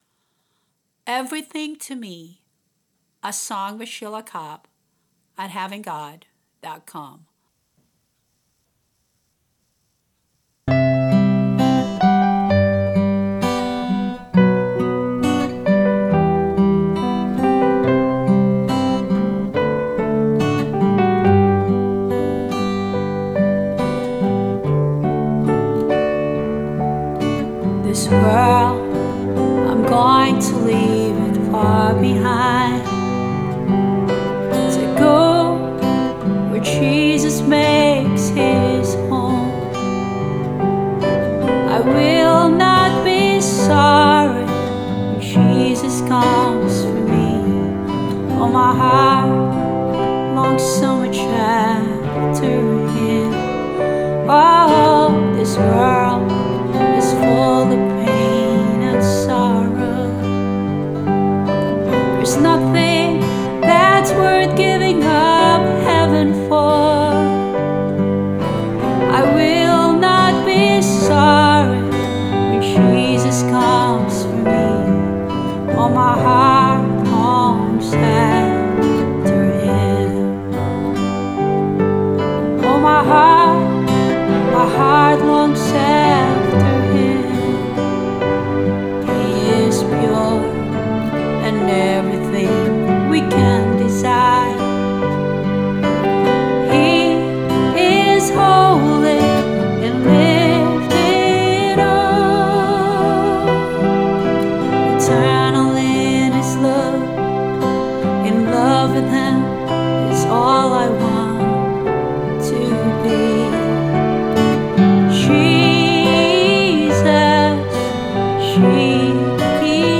Vocals, guitar and bass
Keyboard and strings